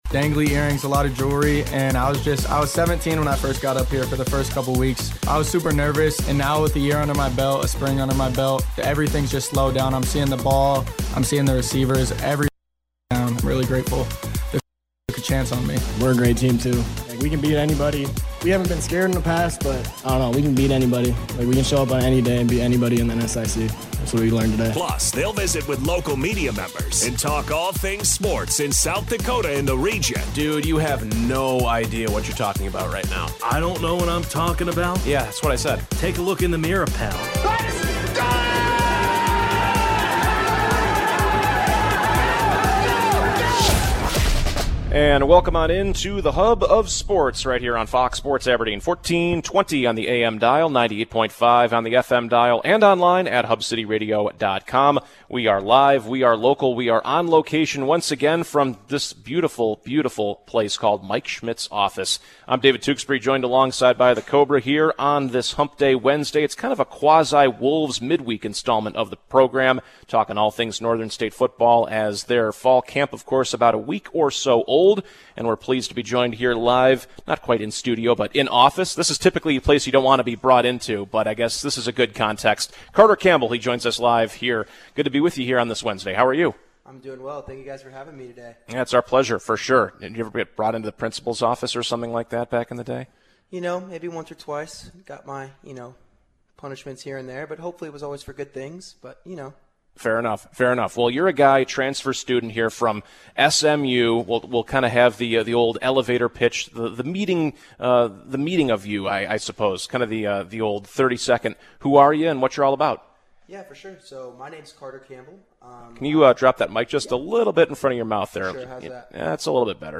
The Sports Hub: Wednesday, August 20th, 2025 All things Northern State Football as the guys are LIVE from NSU Fall Camp.